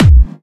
VEC3 Clubby Kicks
VEC3 Bassdrums Clubby 025.wav